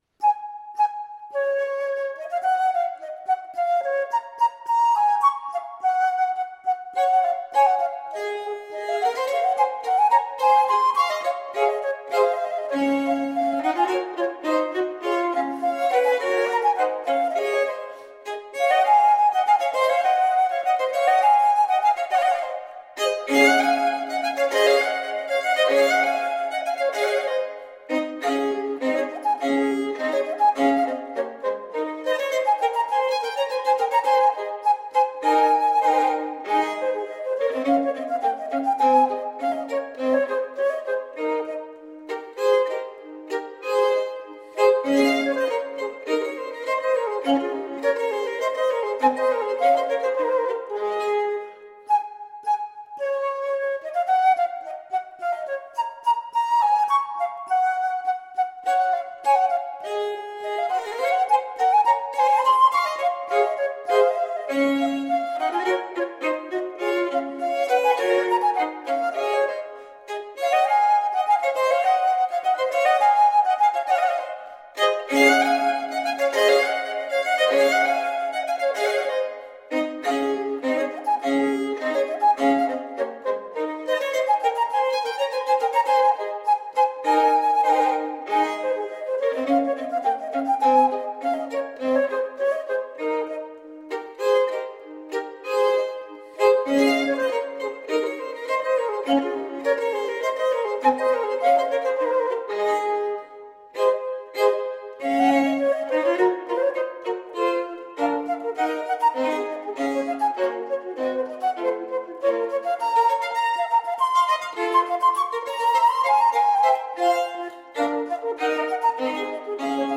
Exquisite chamber music.